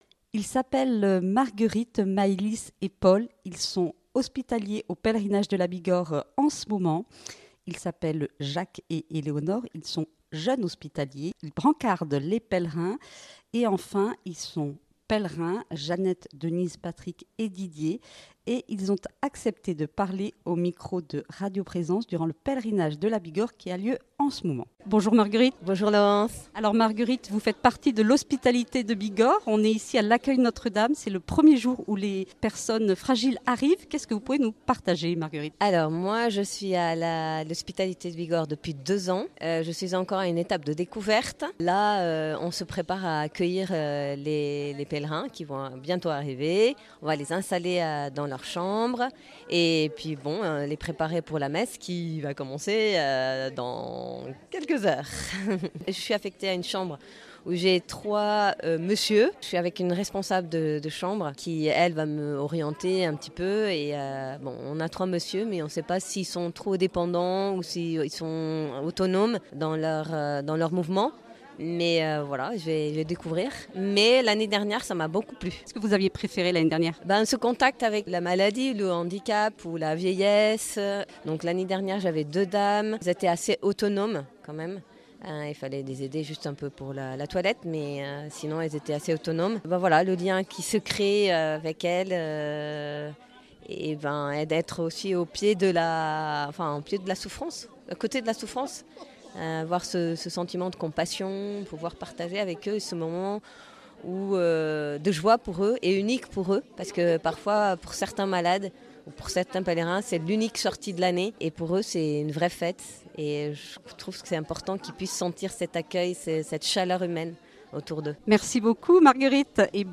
Hospitaliers, JHO et pèlerins, ils arrivent tous au pèlerinage de la Bigorre avec beaucoup de joie !